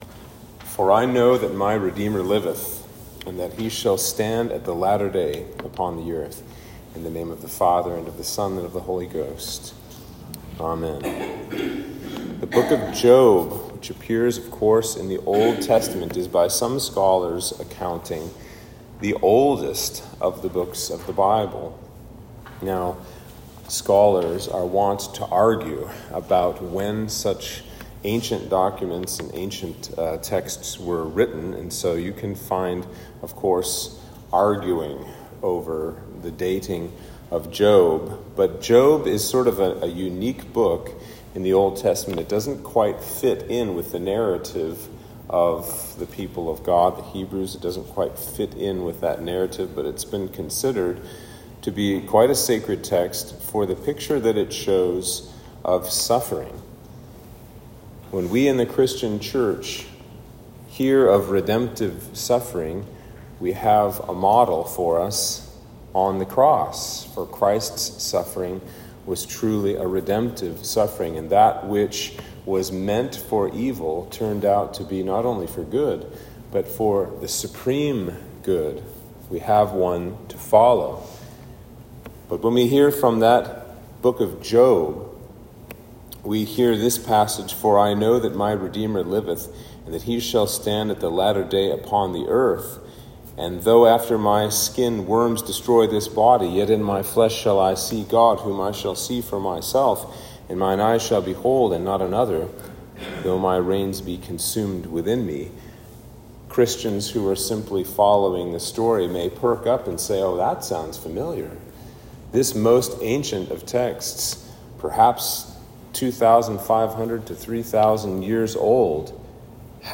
Sermon for Easter 4